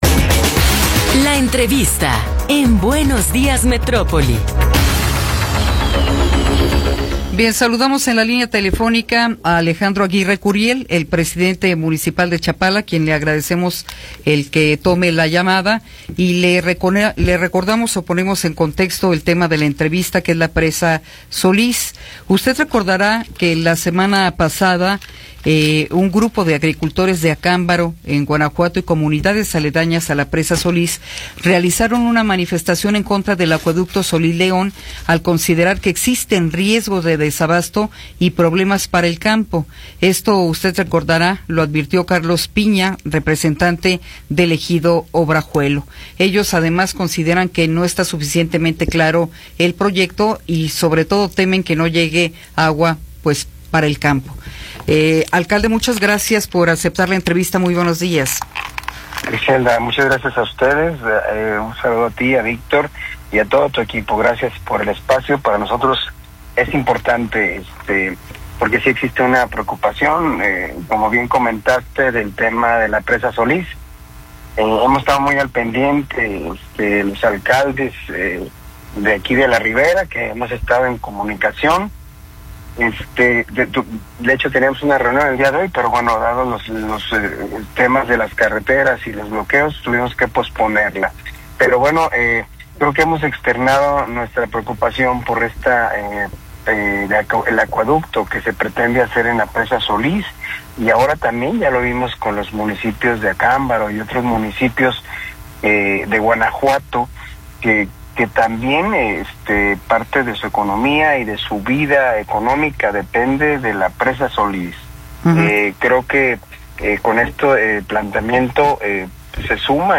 Entrevista con Alejandro Aguirre Curiel
Alejandro Aguirre Curiel, presidente municipal de Chapala, nos habla sobre el conflicto en torno al acueducto Solís-León.